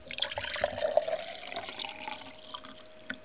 mutfak ses efektleri:)